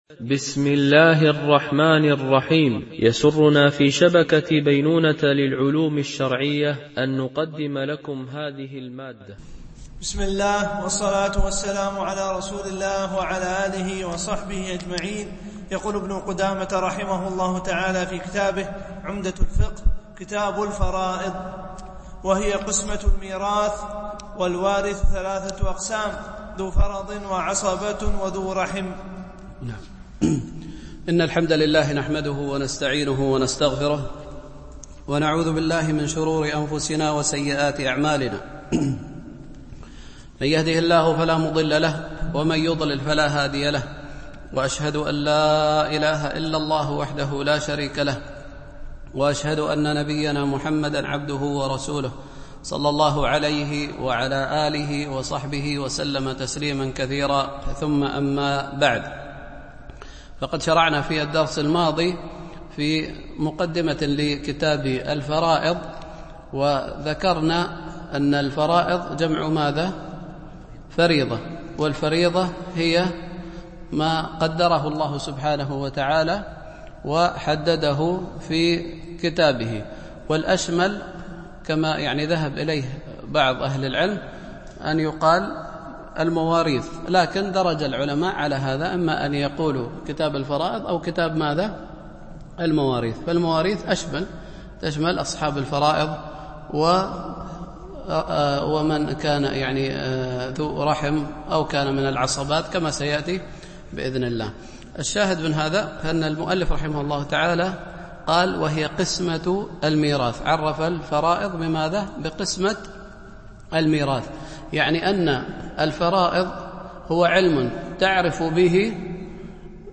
شرح عمدة الفقه ـ الدرس 85 (كتاب الفرائض)
MP3 Mono 22kHz 32Kbps (CBR)